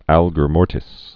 (ălgər môrtĭs)